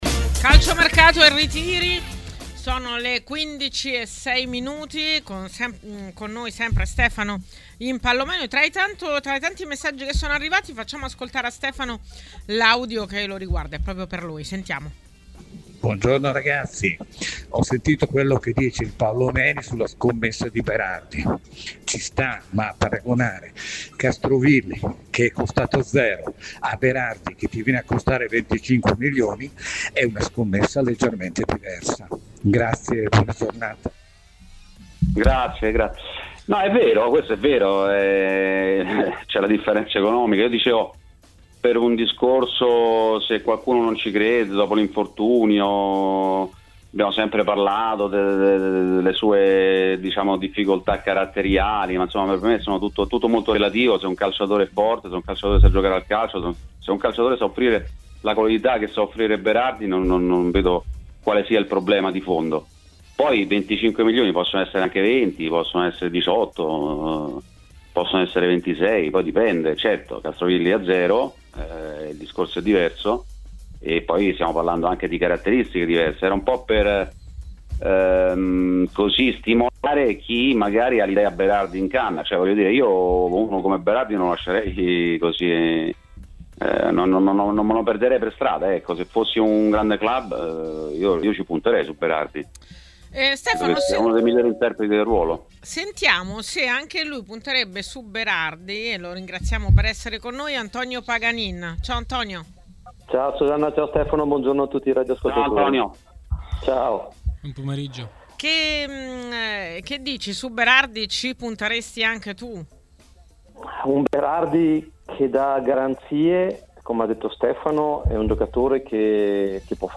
Le Interviste
A parlare dei temi del giorno a TMW Radio, durante Calciomercato e Ritiri, è stato l'ex calciatore Antonio Paganin.